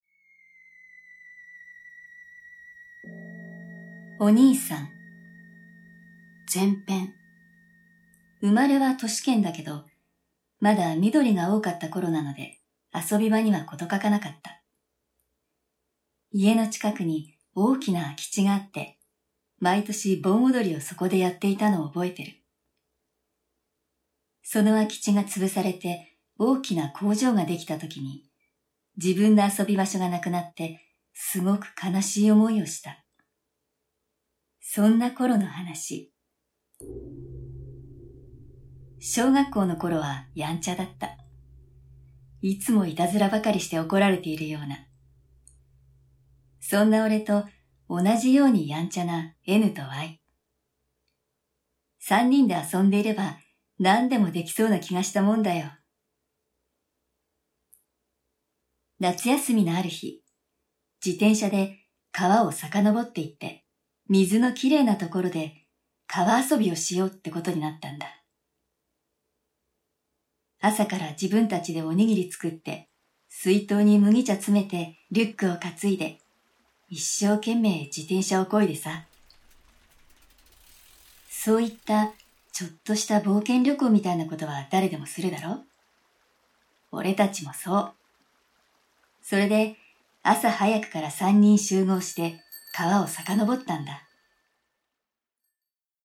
[オーディオブック] ネットであった本当に怖い実話 3D Vol.3-2 〜Q〜 (上)
SEにもこだわり、最先端技術を駆使し、擬似的に3D音響空間を再現、格別の臨場感を体感出来ます！